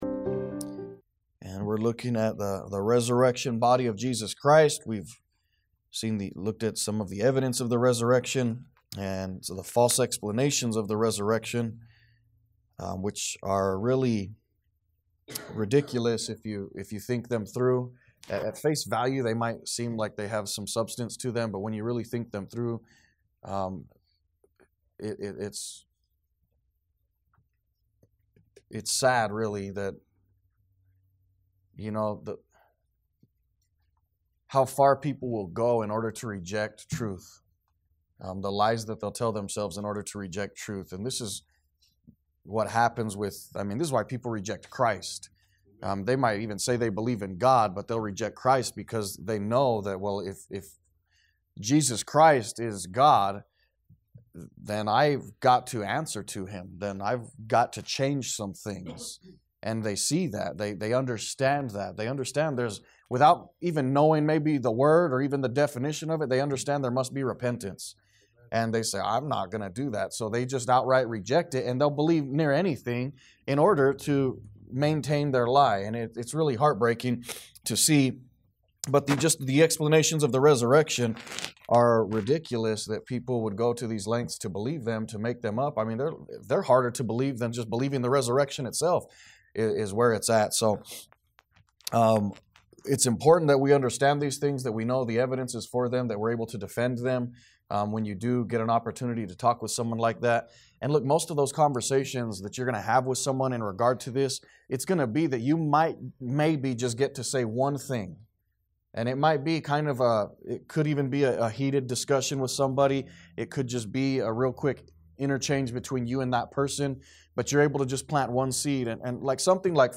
A message from the series "Stand Alone Messages."